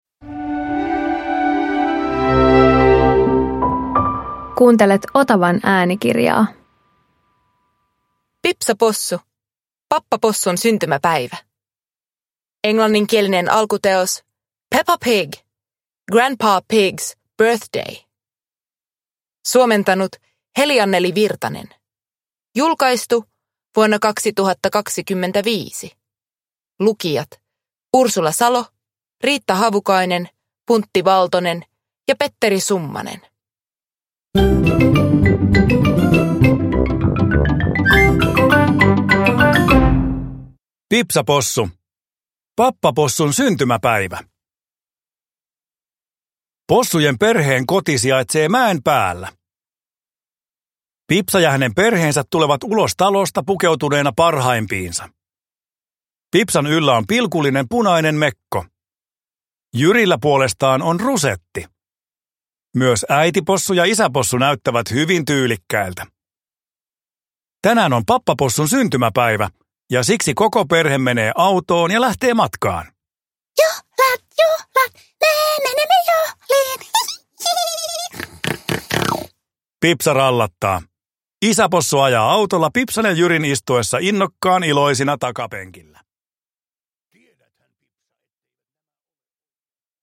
Pipsa Possu - Pappapossun syntymäpäivä – Ljudbok